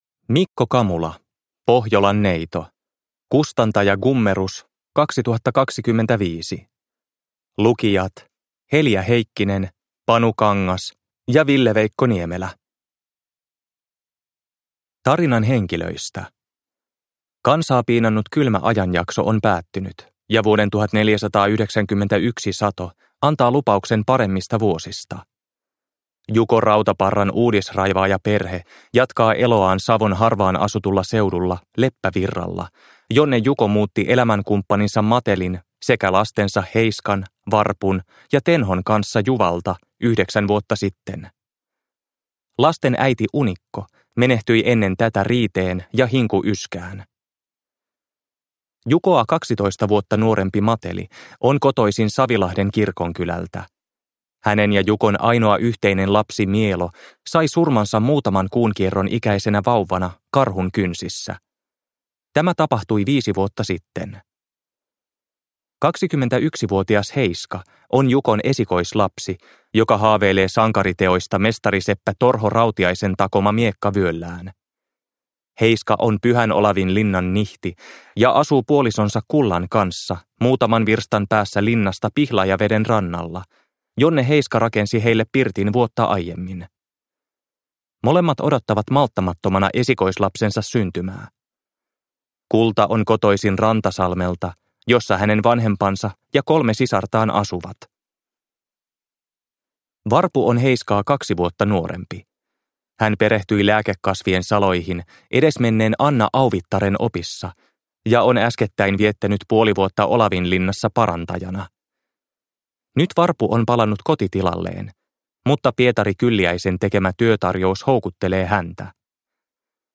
Pohjolan neito – Ljudbok